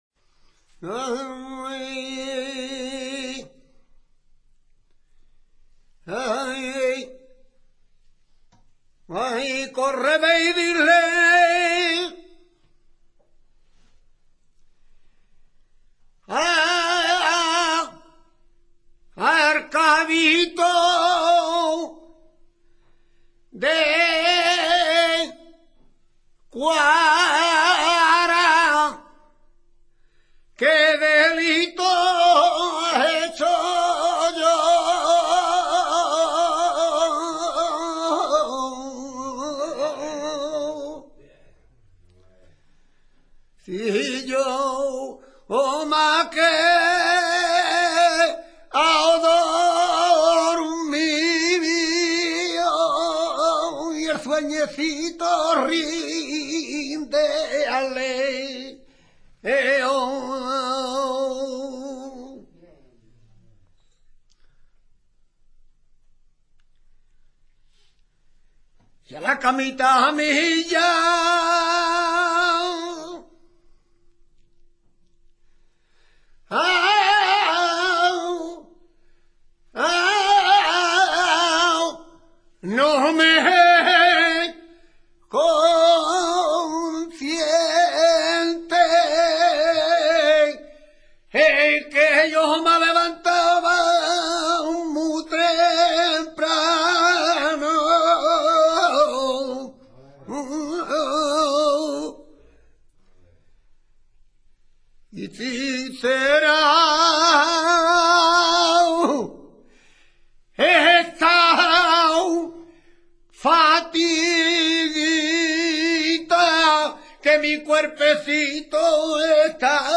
Cante flamenco con coplas de cuatro versos octos�labos, el segundo y el cuarto asonatados, que se suele rematar con un terceto imperfecto.
tona.mp3